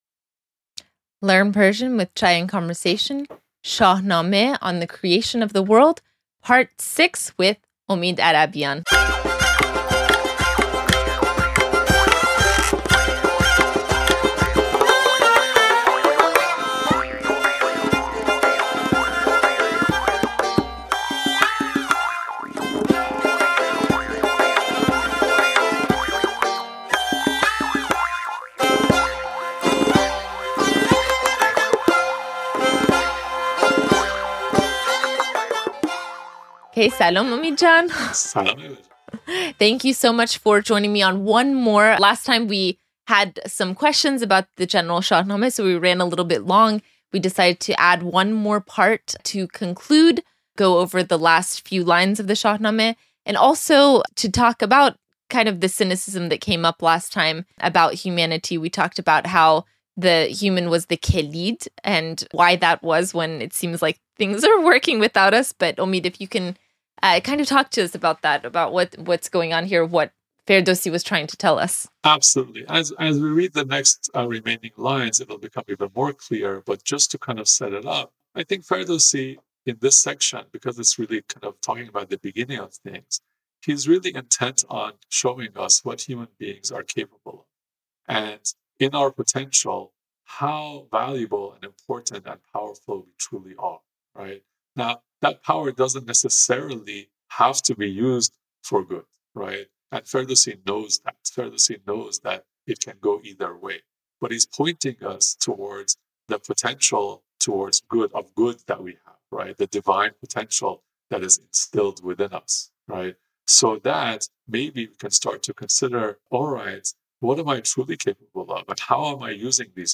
Lesson 106: Shahnameh, On the Creation of the World, Part 6 - Learn Conversational Persian (Farsi)